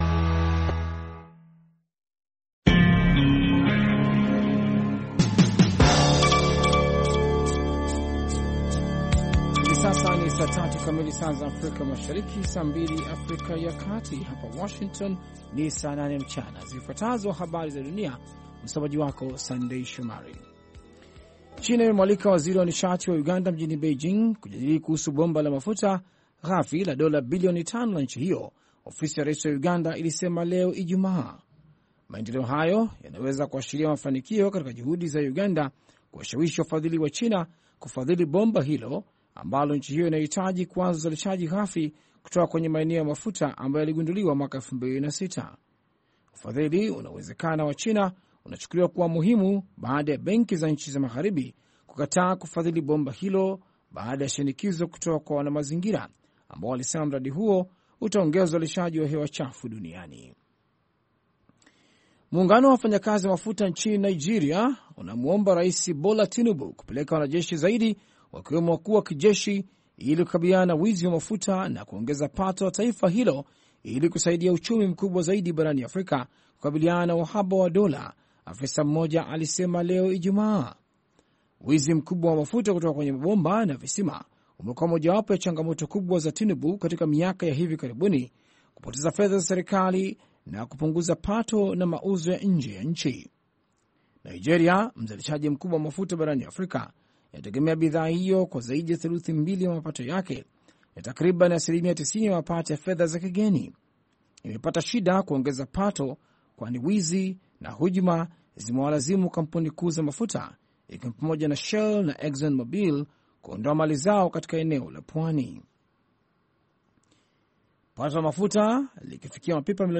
Meza ya waandishi ikijadilia masuala muhimu ya wiki likiwemo lile la kuteuliwa kwa Waziri Mkuu wa kwanza mwanamke nchini DRC.